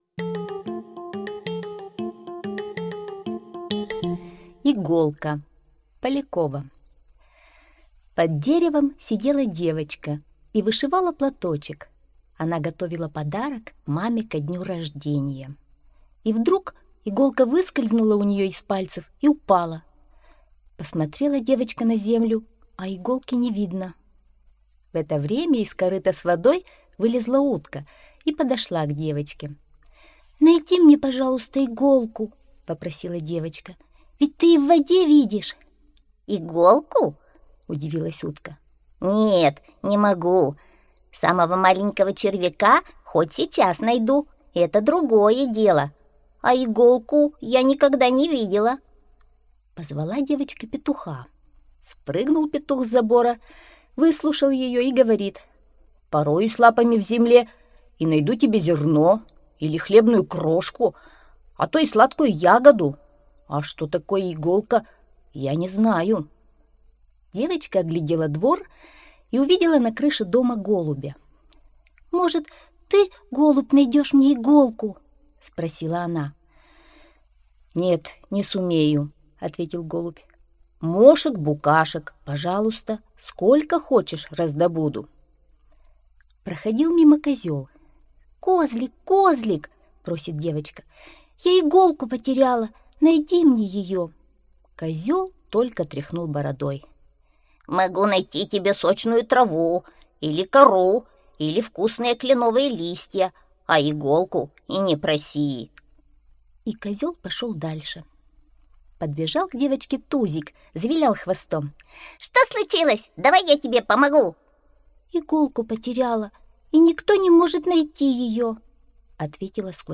Иголка – аудиосказка по мультфильму